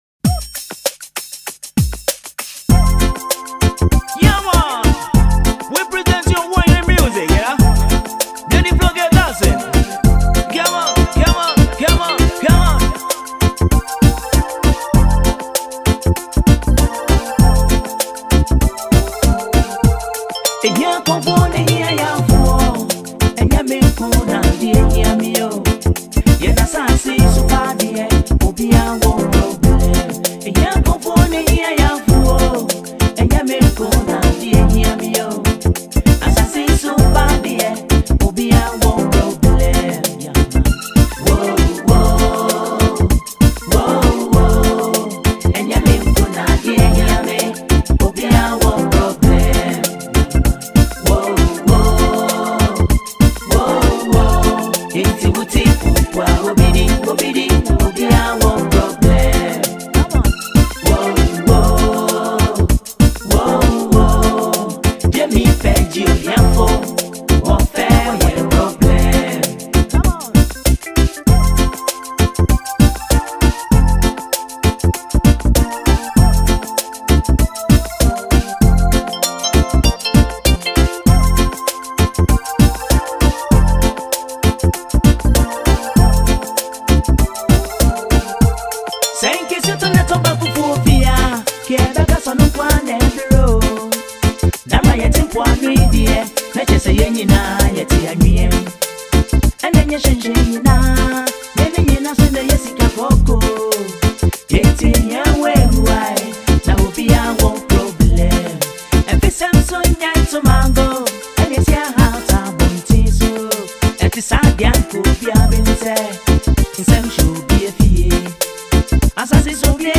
highlife
With his signature smooth vocals and rich storytelling